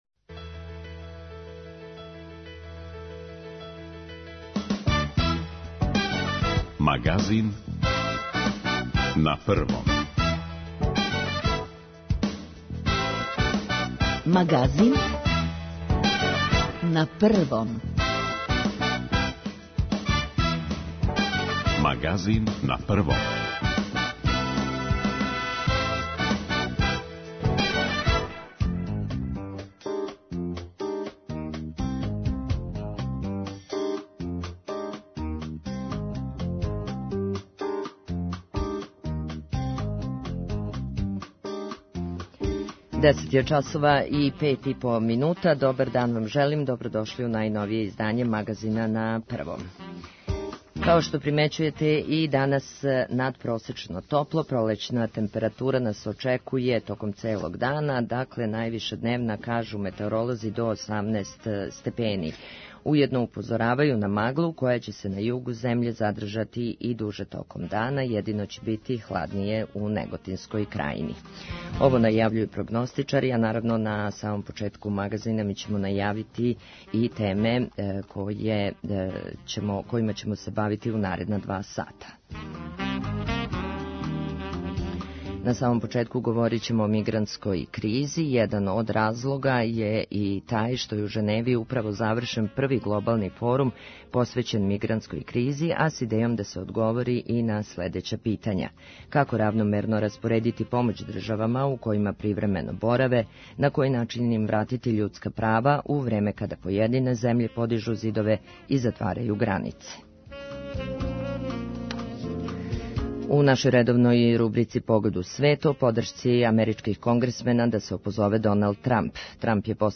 Да ли су на Форуму постигнути конкретни договори, у каквој је позицији Србија и како мигранти живе код нас чућете од Светлане Велимировић - заменице комесара за избеглице и миграције.